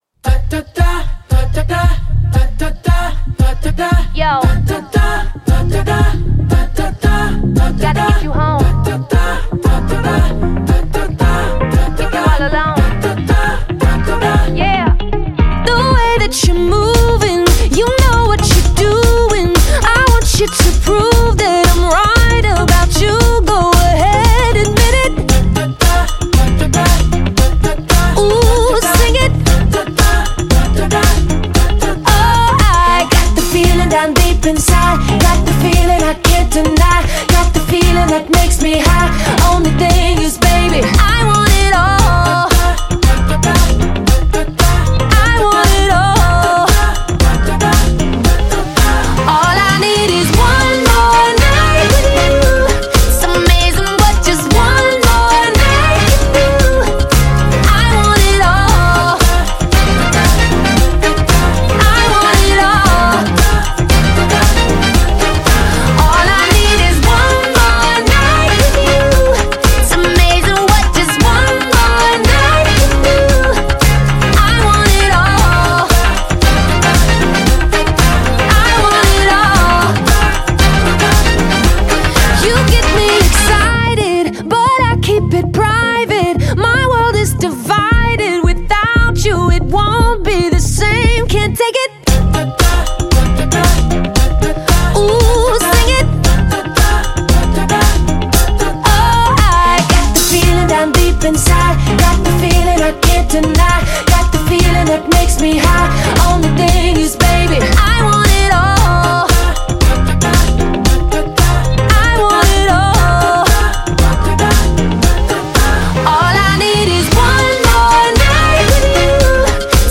还是那么朗朗上口，节奏感很带劲。